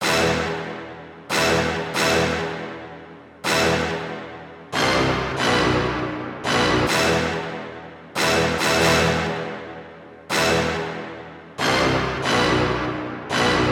邪恶的氛围
描述：一种缓慢渐强，险恶的氛围。用Ableton记录。
标签： 快感 恐惧 来势汹汹 环境 反恐 戏剧 威胁 阴险 狠毒 血统
声道立体声